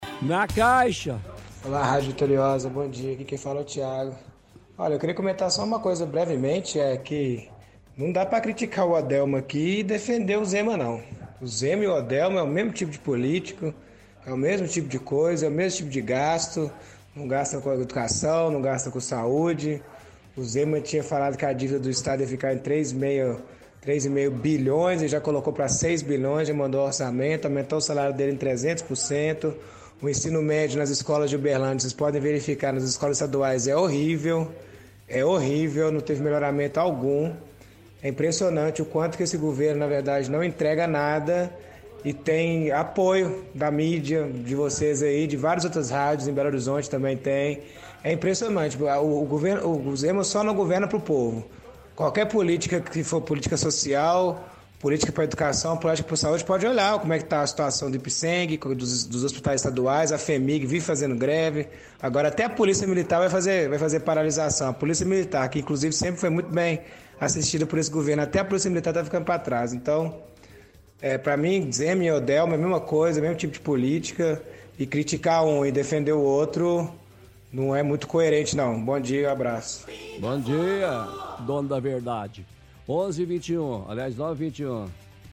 – Ouvinte defende que não é possível criticar Odelmo e defender Zema ao mesmo tempo, pois fazem o mesmo tipo de política. Faz críticas ao governo estadual.